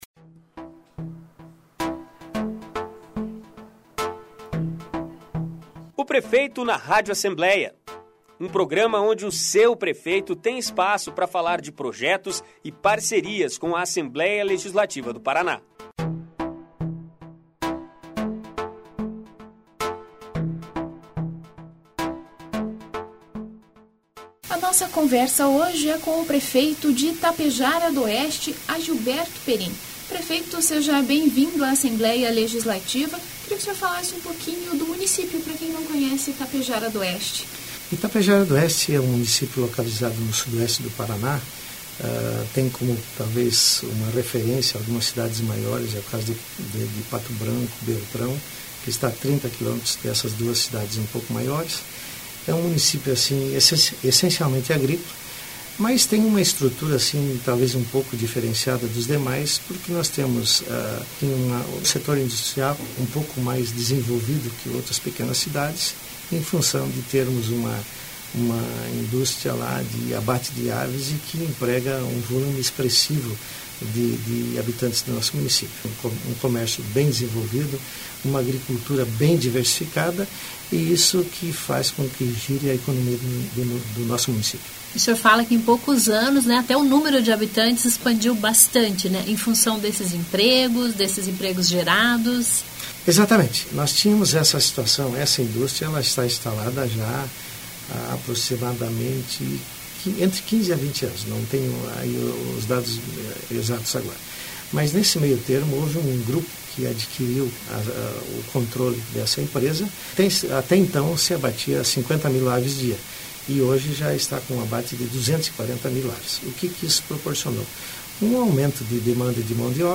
Ouça aqui a entrevista com o prefeito Agilberto Perin (PSDB),  de Itapejara do Oeste. Ele fala sobre desafios e expectativas do mandato, que começou em janeiro de 2017.